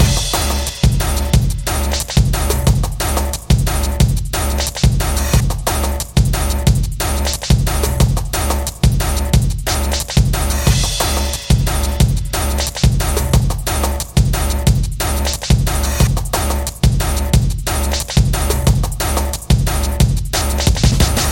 描述：工业dnb循环180bpm
Tag: 180 bpm Drum And Bass Loops Drum Loops 3.59 MB wav Key : Unknown